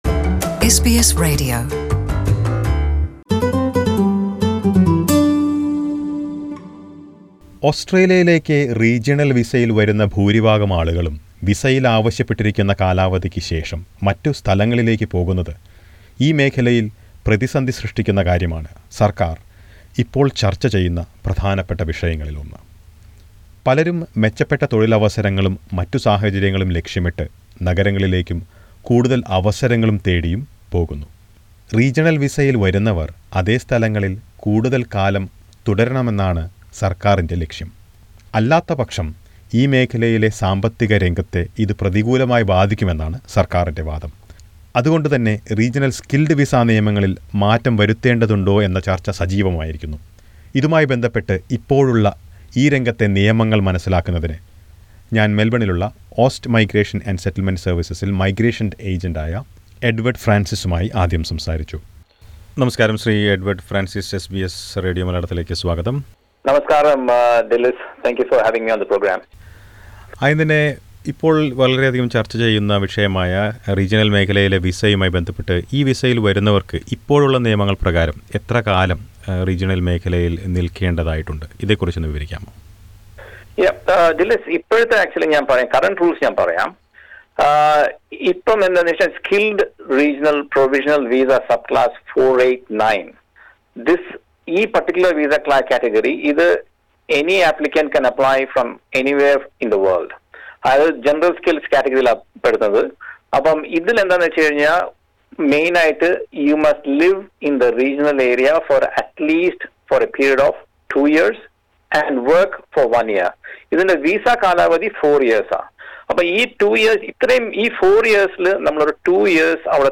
കൂടാതെ, റീജിയണൽ വിസയിൽ ഓസ്‌ട്രേലിയയിൽ എത്തിയ ചില മലയാളികളോട് എസ് ബി എസ് മലയാളം സംസാരിച്ചു.